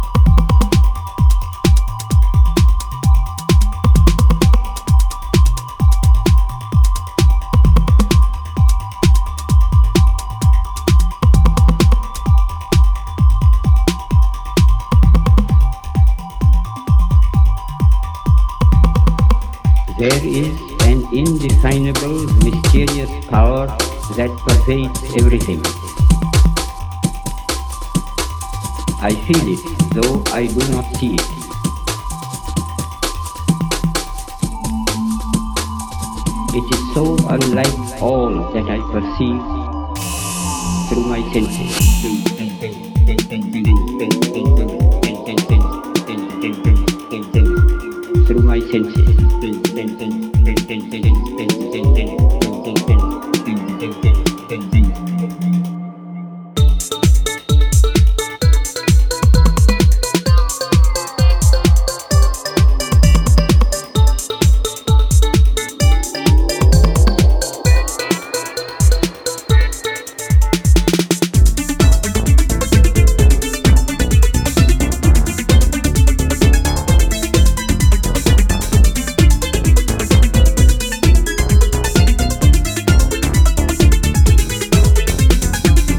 a profound and introspective gem
transport you into a perpetual hypnotic state.